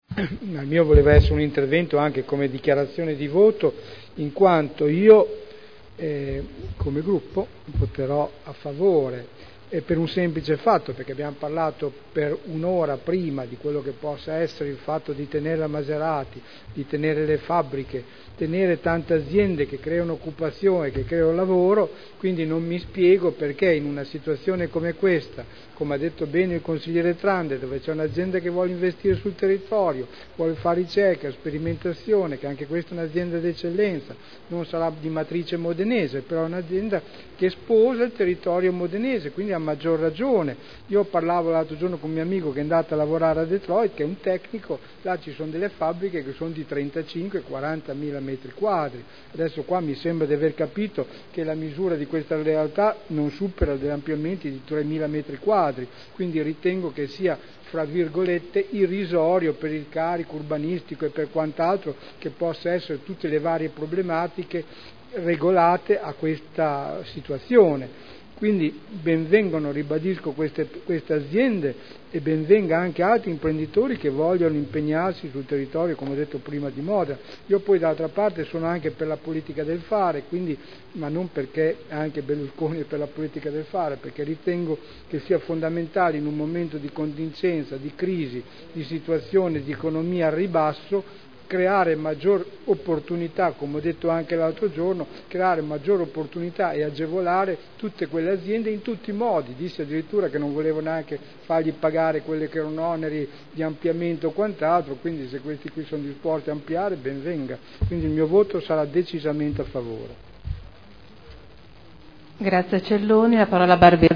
Sergio Celloni — Sito Audio Consiglio Comunale